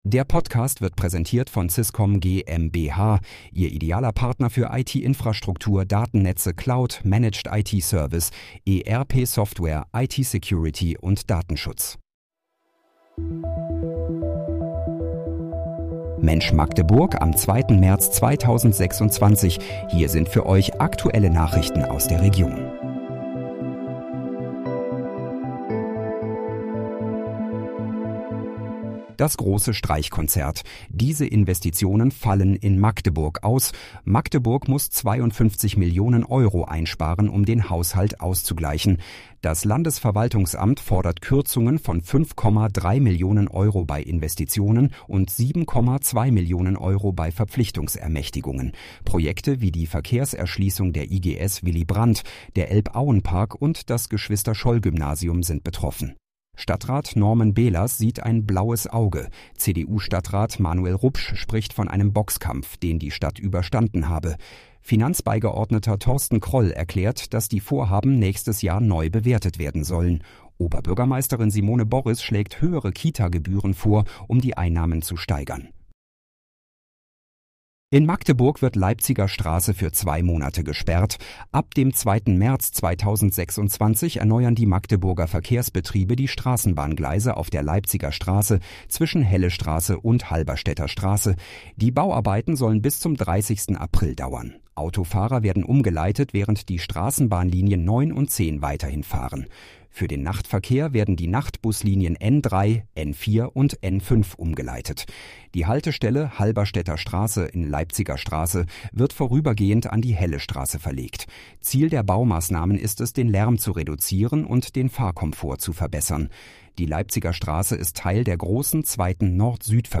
Mensch, Magdeburg: Aktuelle Nachrichten vom 02.03.2026, erstellt mit KI-Unterstützung